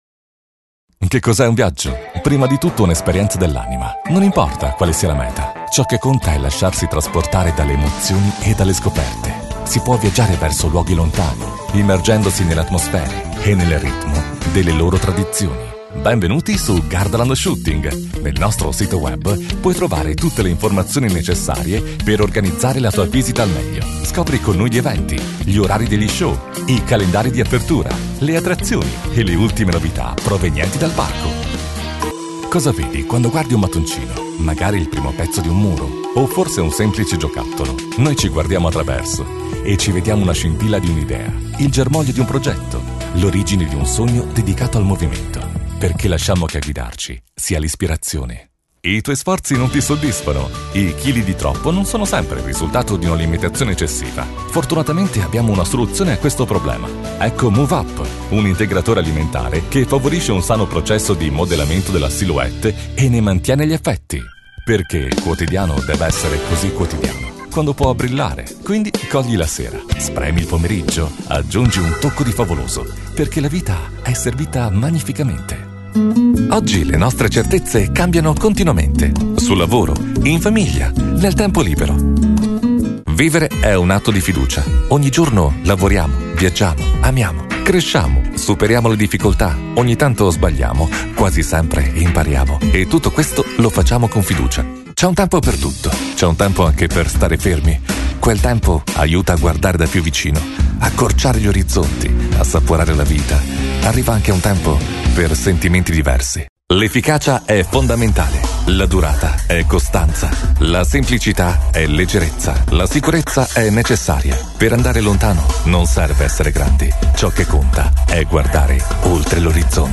Sprechprobe: Industrie (Muttersprache):